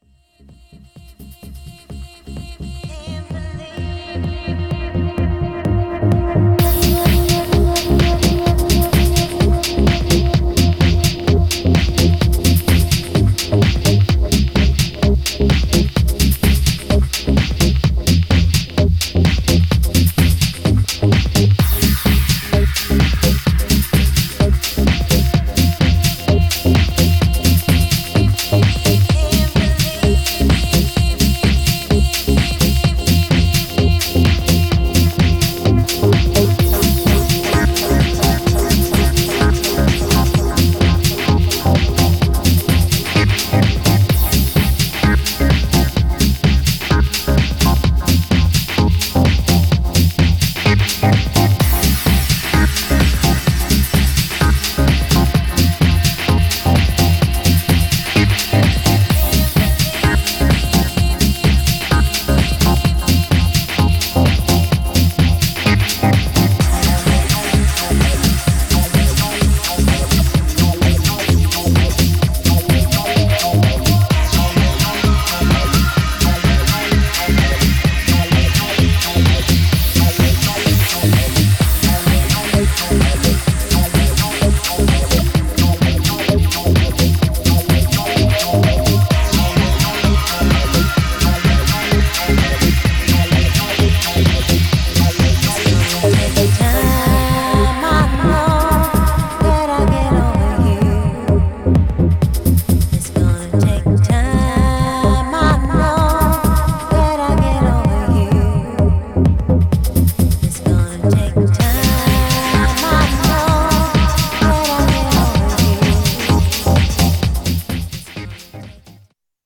Styl: Progressive, House, Breaks/Breakbeat, Trance